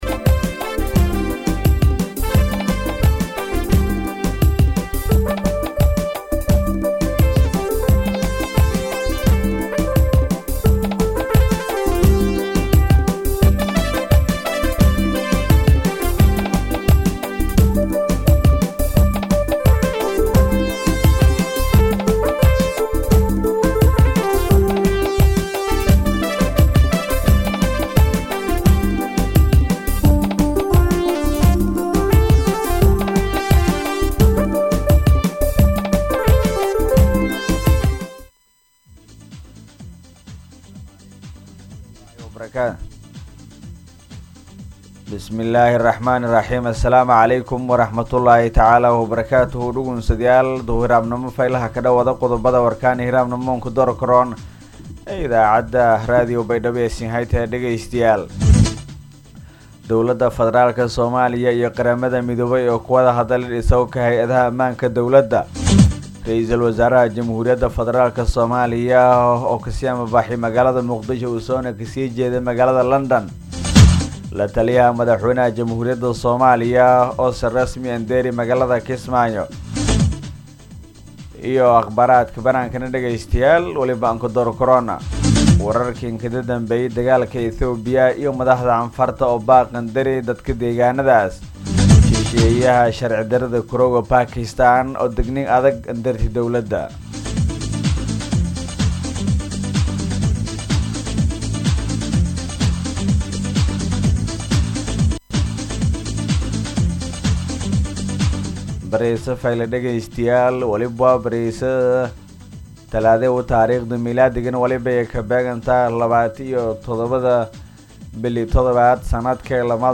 Subax-war.mp3